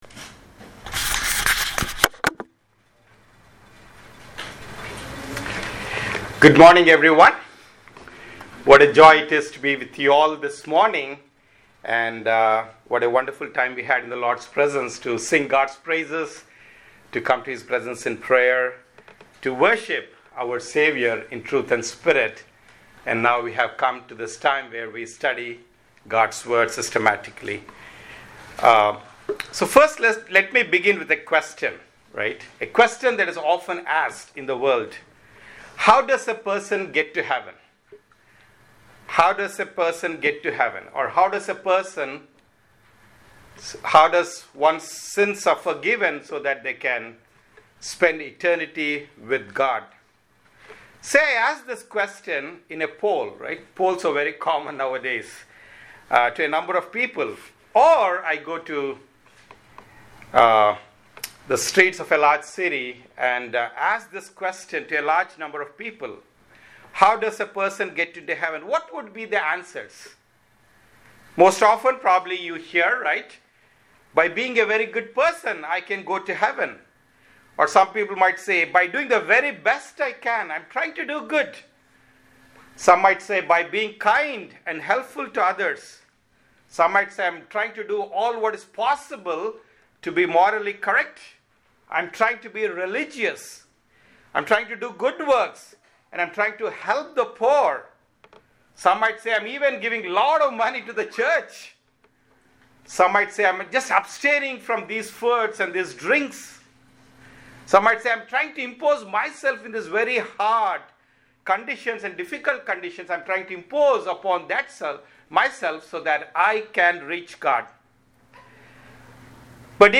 Sermons – Ardsley Bible Chapel